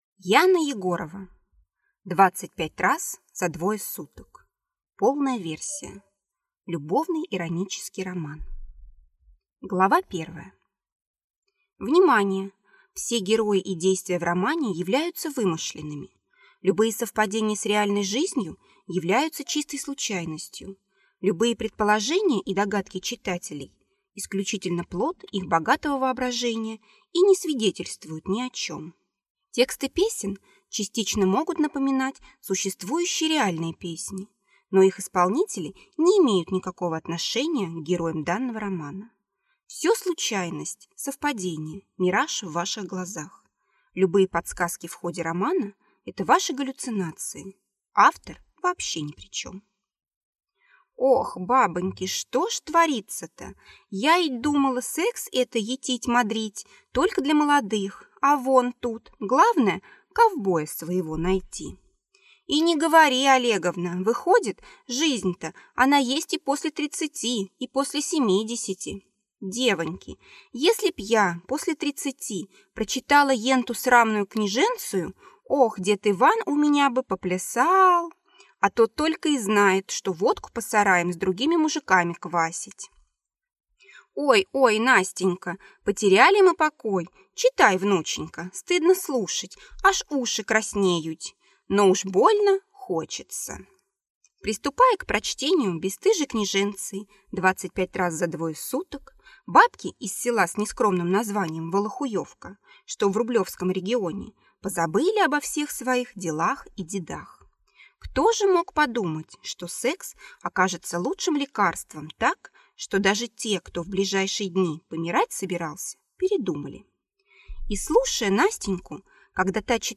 Аудиокнига 25 раз за 2 суток.
Прослушать и бесплатно скачать фрагмент аудиокниги